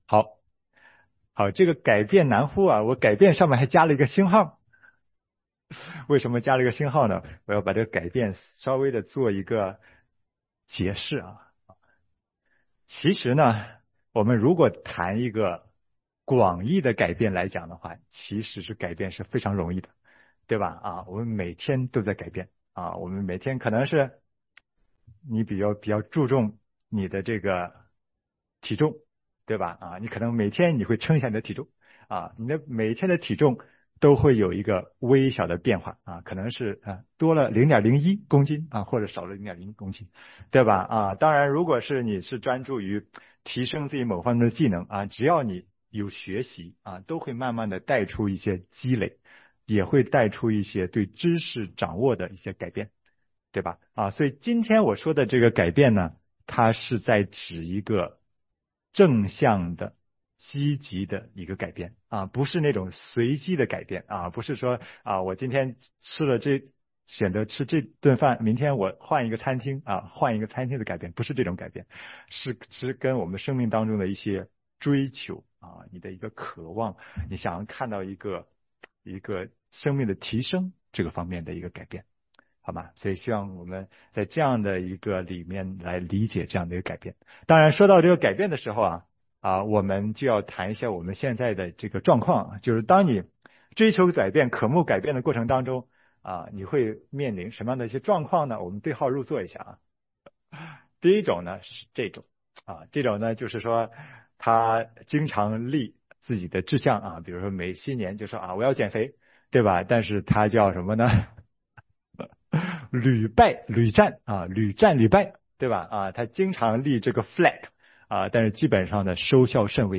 更新 福音主日 以弗所书